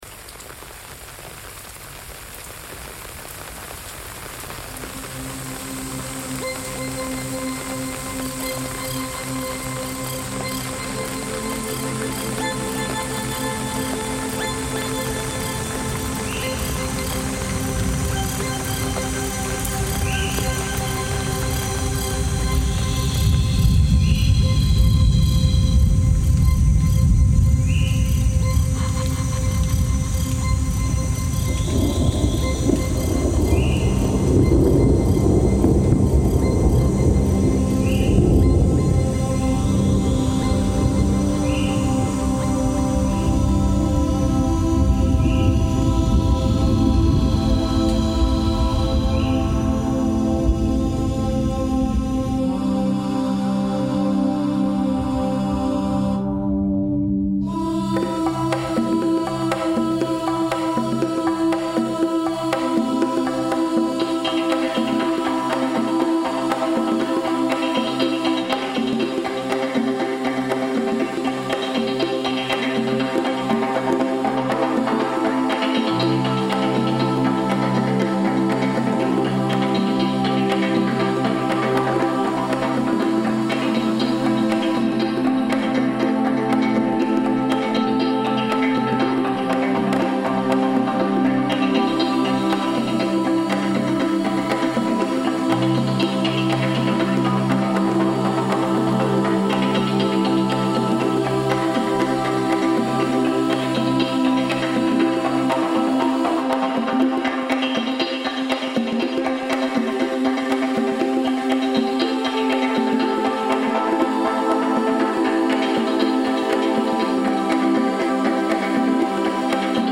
In contrast to this raw energy, I was also moved by the moments of quiet that settle between thunderclaps: the subtle stirrings of wildlife pausing, then reawakening. Through shifting energetic textures and beats, dynamic surges, accompanied by soothing harmony this composition seeks to capture that duality.